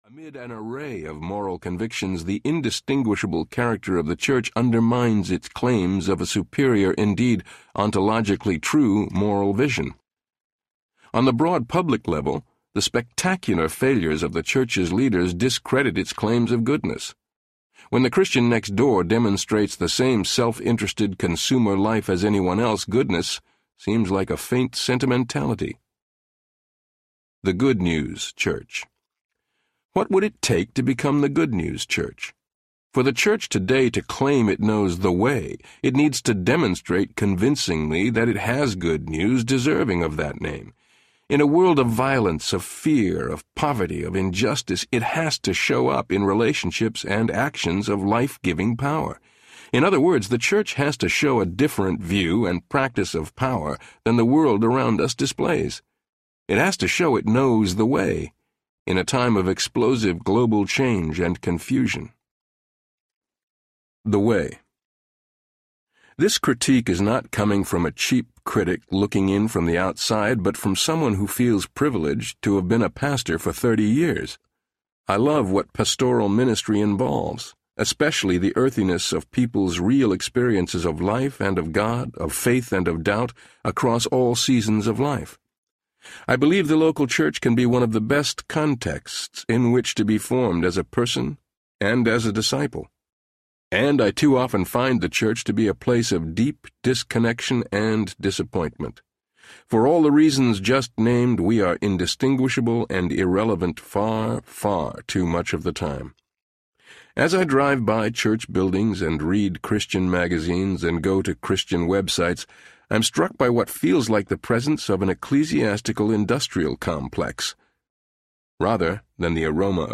Called Audiobook
4.12 Hrs. – Unabridged